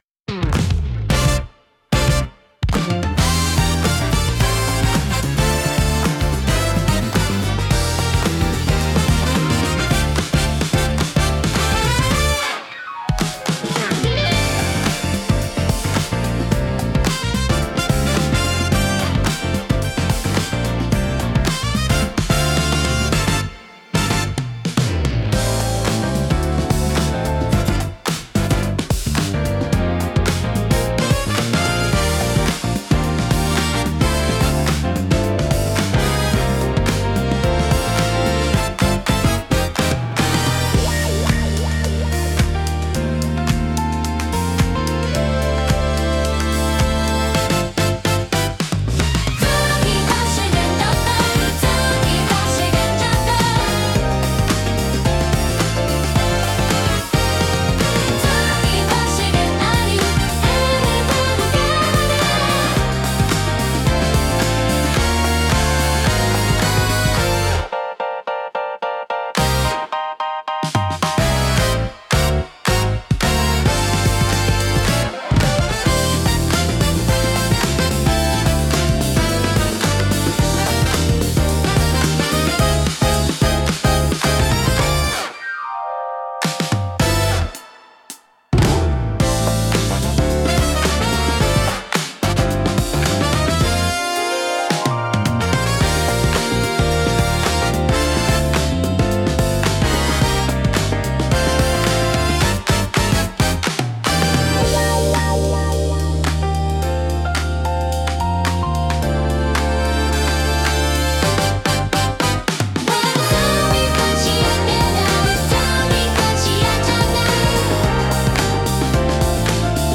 聴く人に洗練された印象を与えつつ、リラックスと活気のバランスを巧みに表現します。